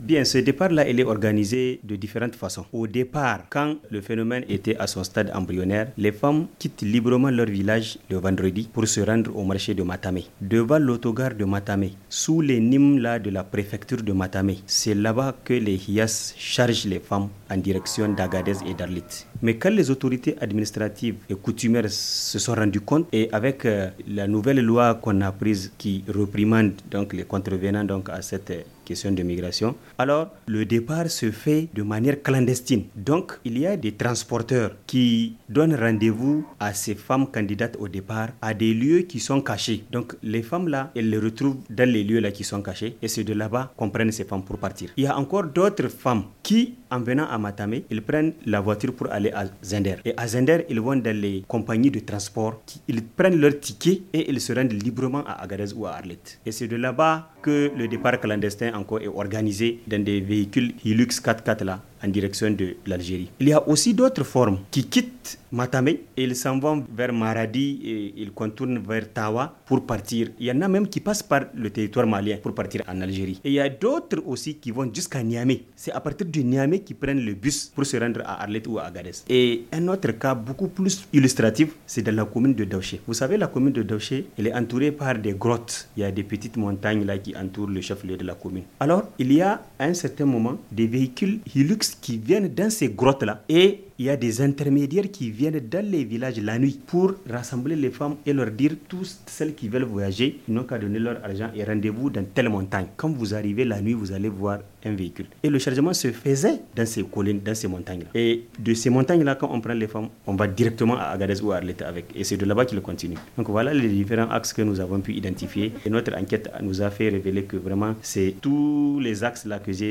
Ces garçons rencontrés dans les rues de Niamey, leur marchandise sur la tête, disent faire de bonnes affaires.